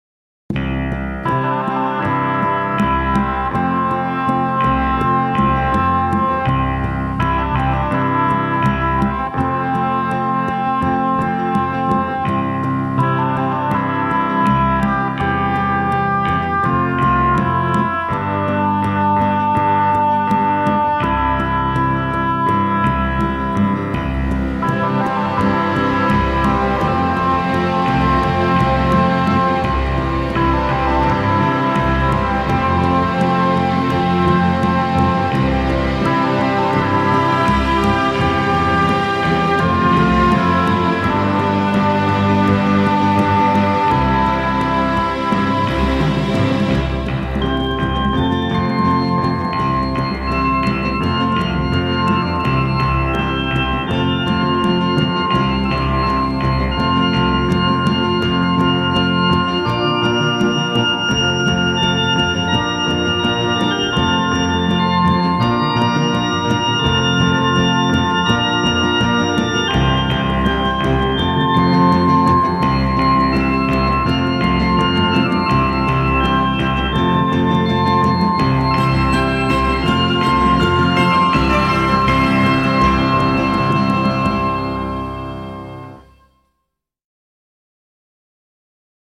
Magistral !